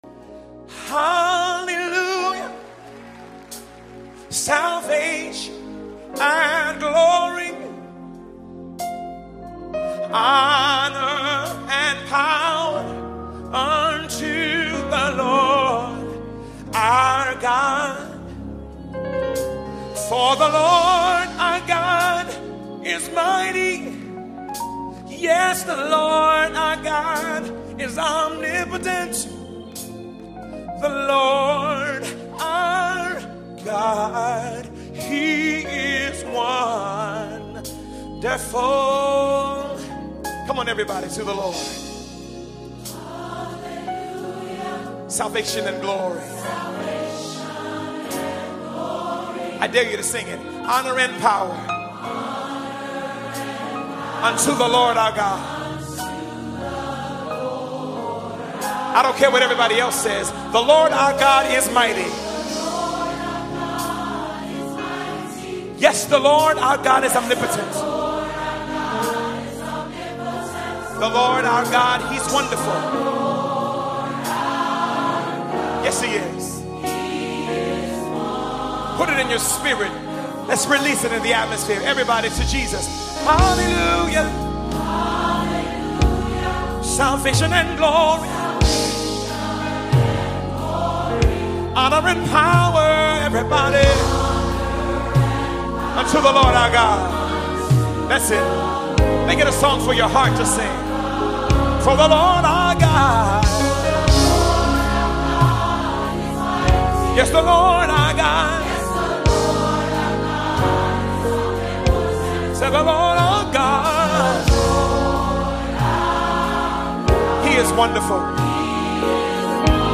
General Choir Reference Tracks
This module includes reference tracks only (lead included).
Reference Track (Lead Included)
Revelation-19_1-Live.mp3